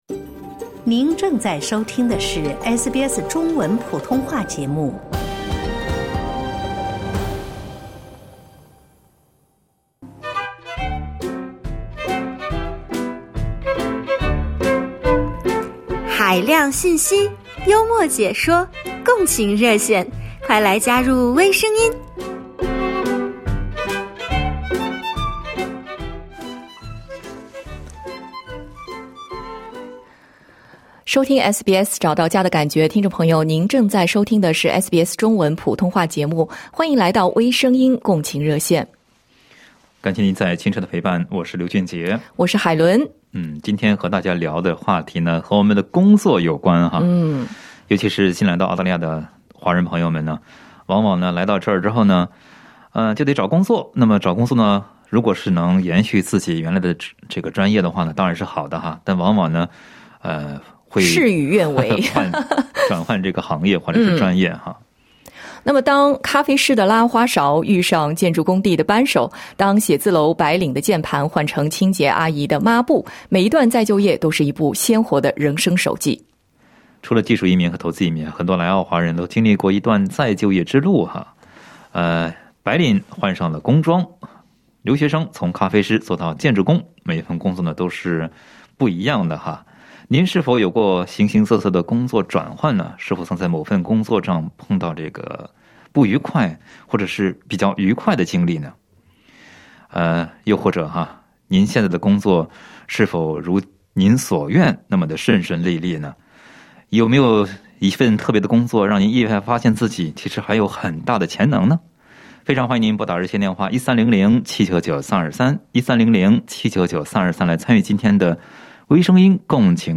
当咖啡师的拉花勺遇上建筑工地的扳手，当写字楼白领的键盘换成清洁阿姨的抹布，每一段 "再就业" 都是一部鲜活的人生手记。点击图标，收听本期【微声音】共情热线！